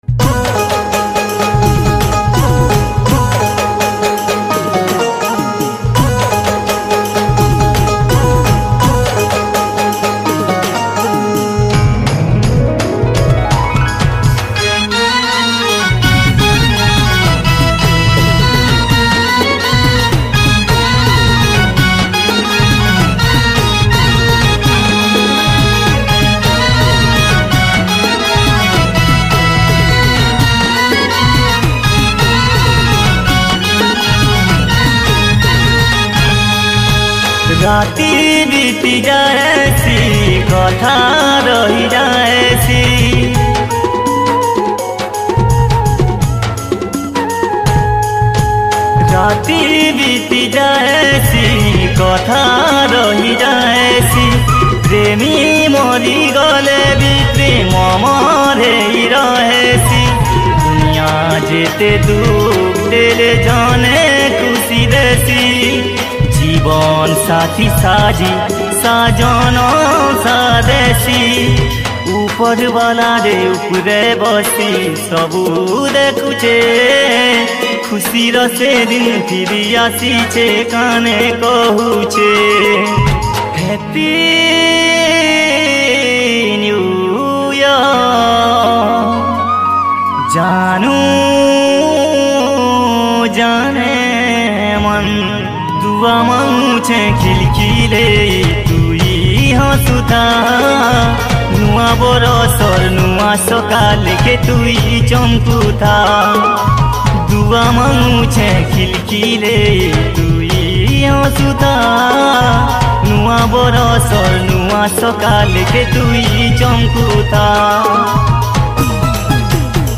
New Year Special Song